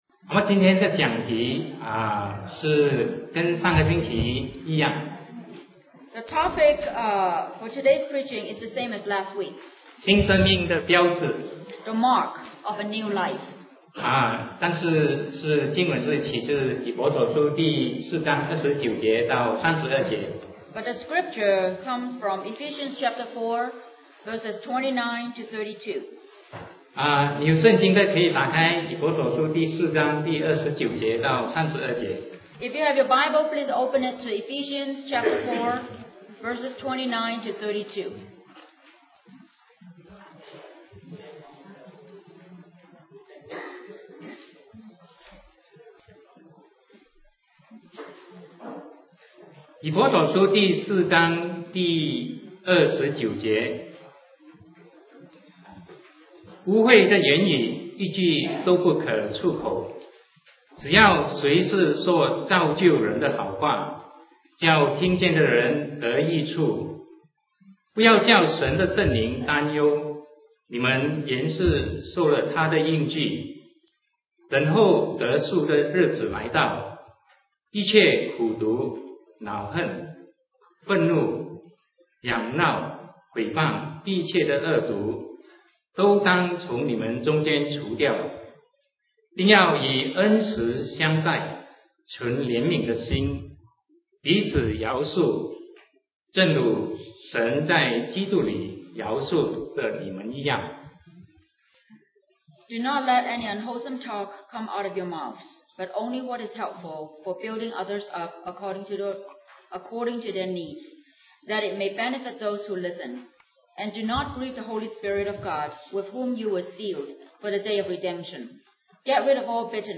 Sermon 2008-09-14 The Mark of a New Life (Pt 2)